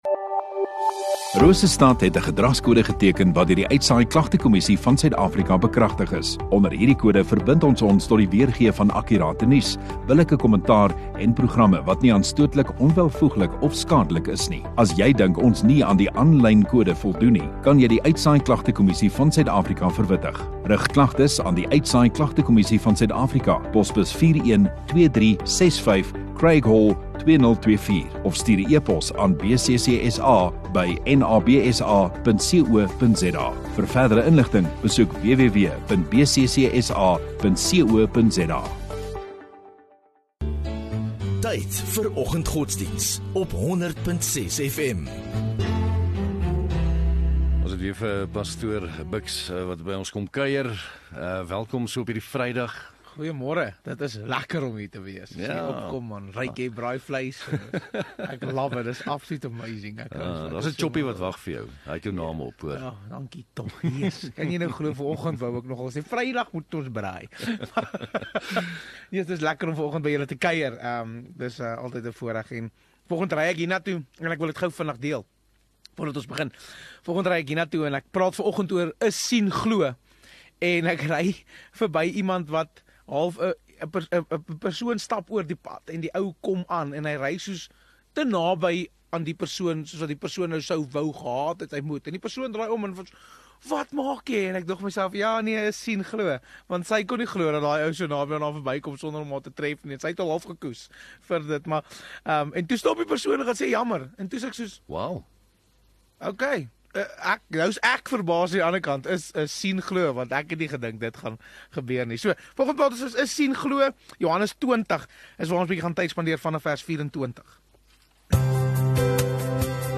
22 Mar Vrydag Oggenddiens